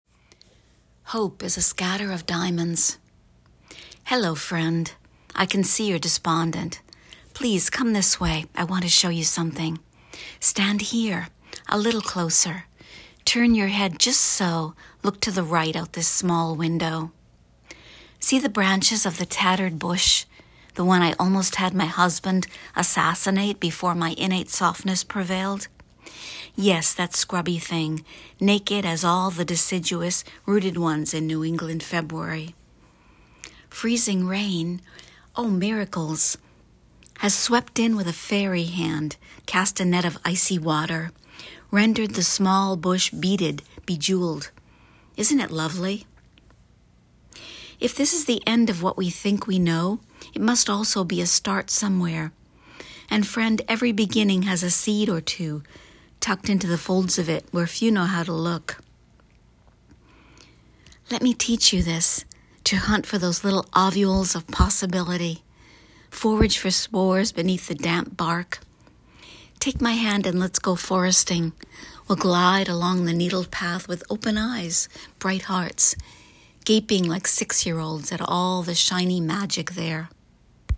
You have a lovely voice but are also a fabulous reader!